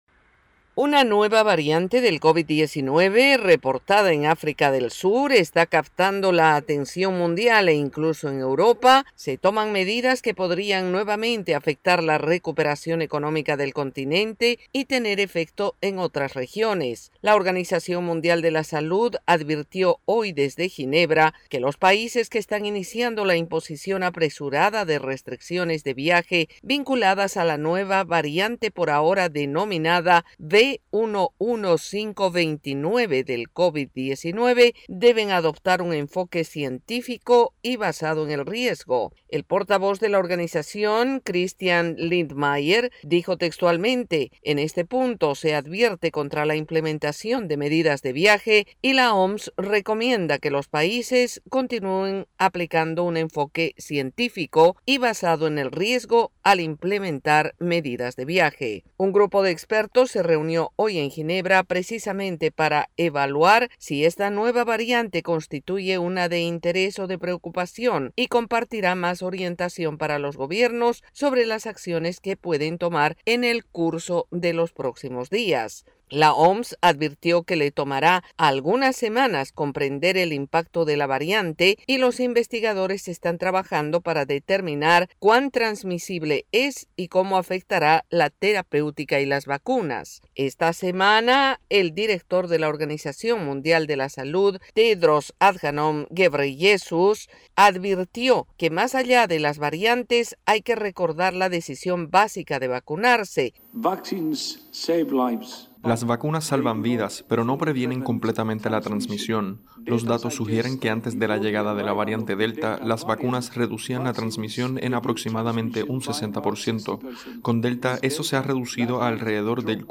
AudioNoticias
desde la Voz de América en Washington DC